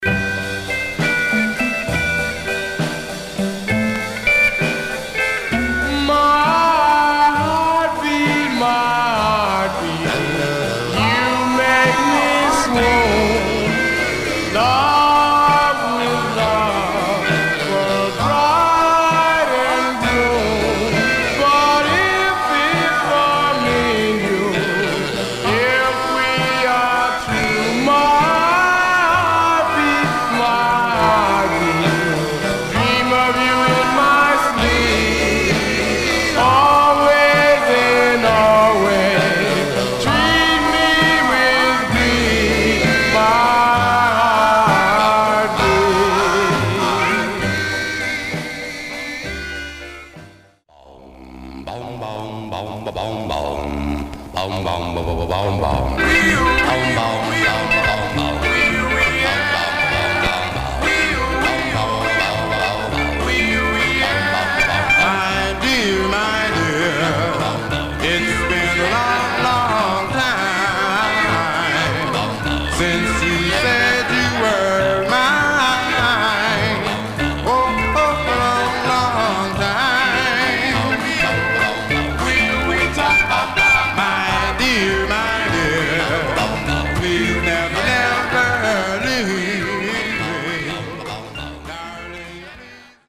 Condition Surface noise/wear Stereo/mono Mono
Male Black Groups